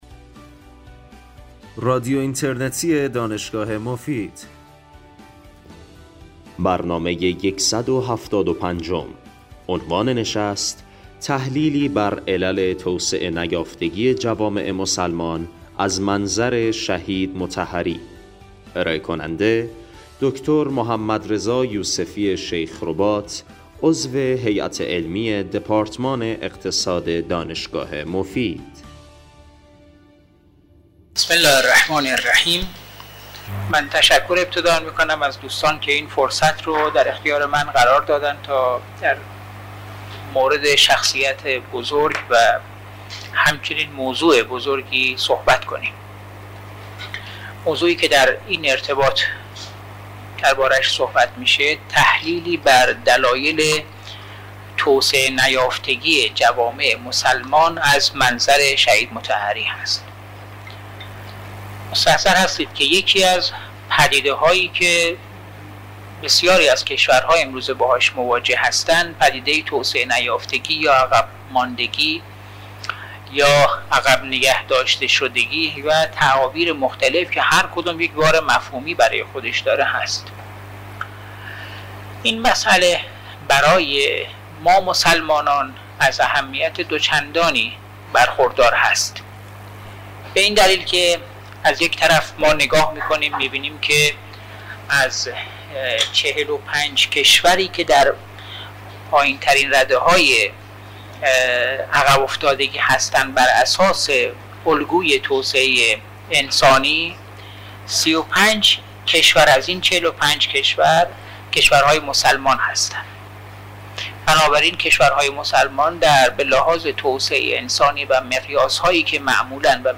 بخش پایانی برنامه به پرسش و پاسخ اختصاص دارد.